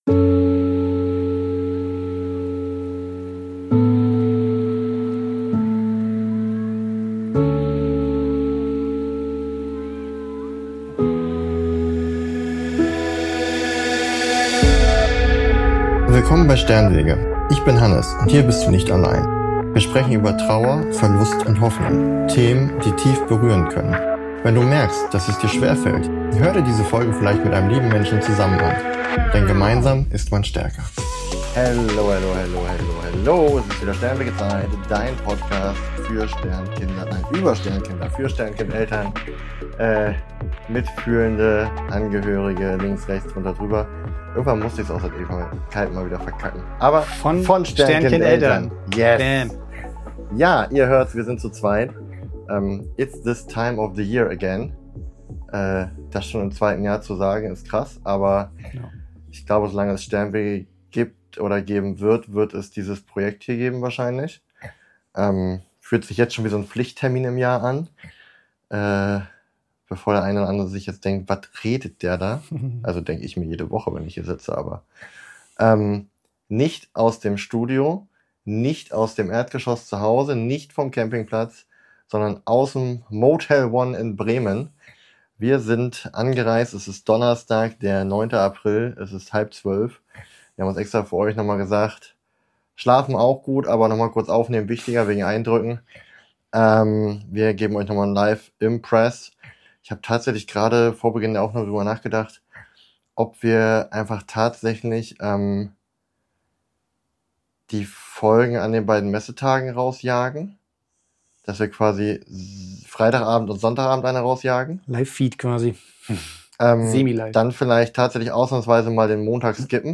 Diese Folge ist kein fertiger Rückblick mit Abstand, wie im letzten Jahr, sondern ein ungefilterter Live-Eindruck direkt vom Tag.